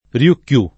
Riù‑Kiù [ r L2 kk L2+ ] → Ryūkyū